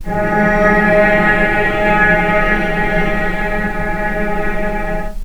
vc_sp-G#3-pp.AIF